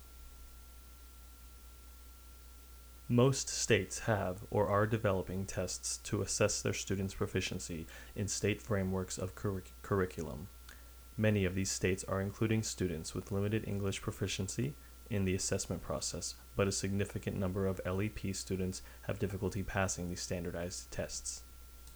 Here is a reading I did with just the mixer.